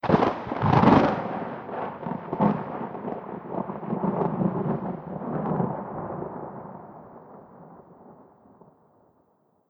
tenkoku_thunder_medium01.wav